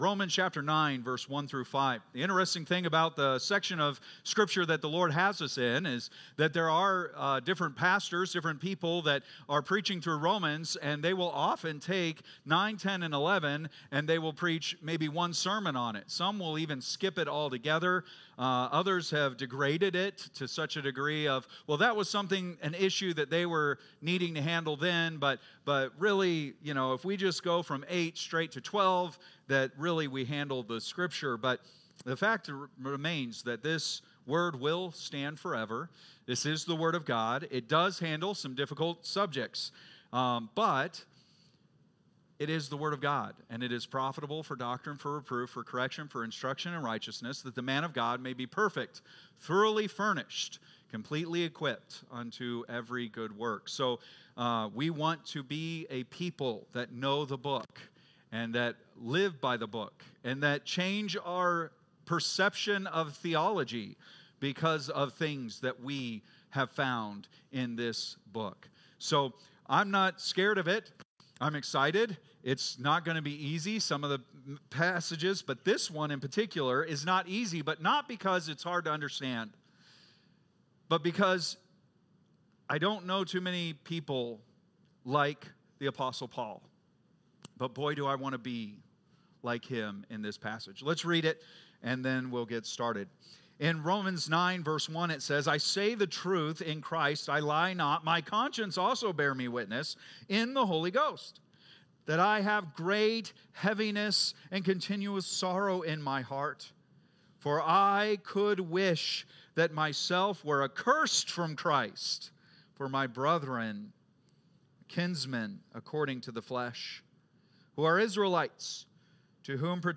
Date: December 1, 2024 (Sunday Morning)